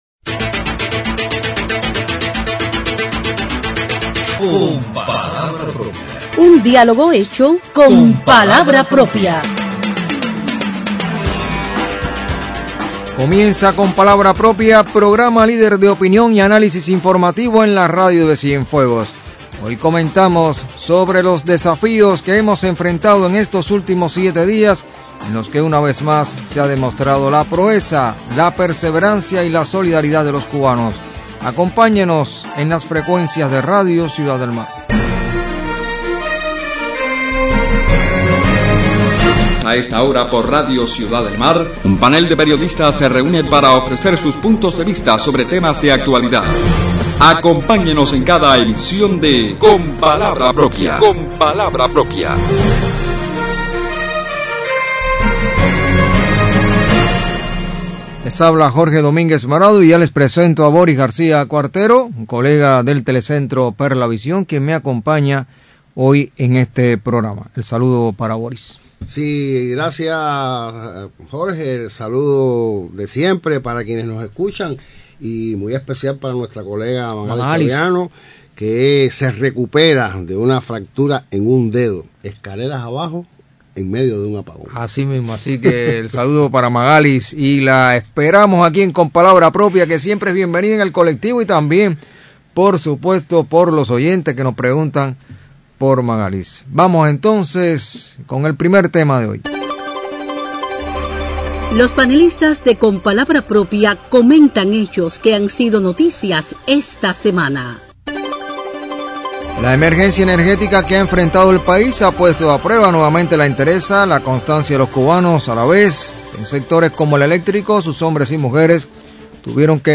La fuerza de los cubanos frente a adversidades como las que hemos enfrentado en estos días motivan el debate de los panelistas de Con palabra propia en la emisión del sábado 26 de octubre.